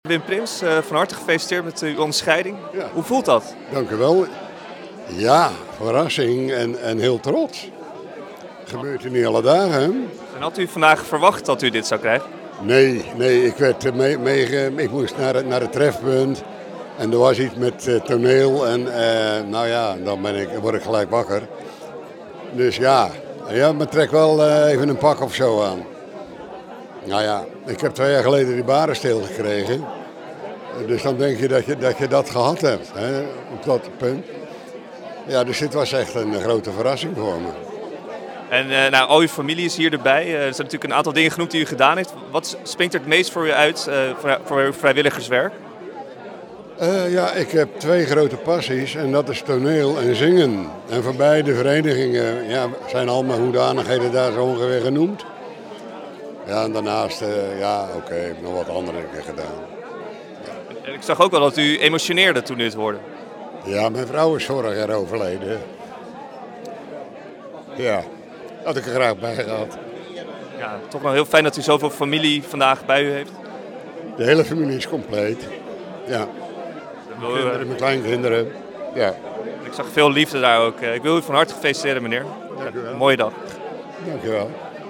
Het interview